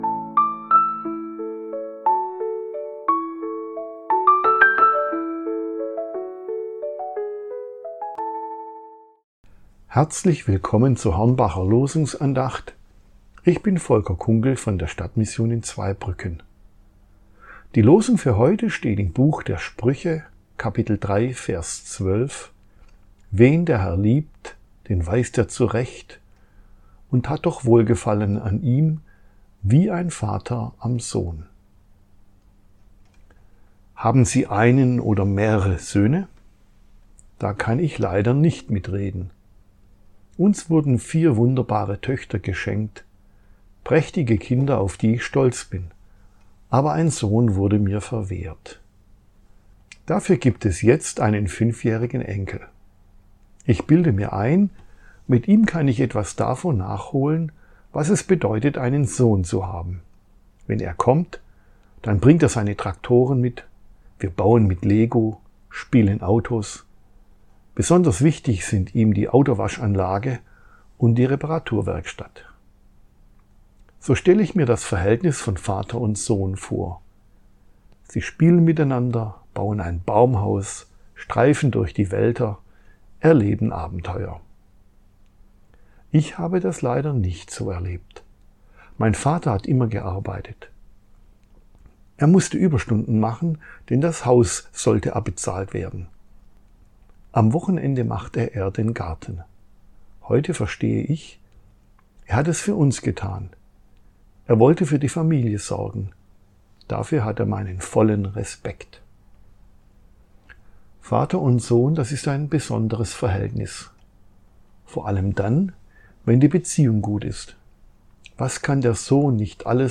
Losungsandacht für Mittwoch, 27.08.2025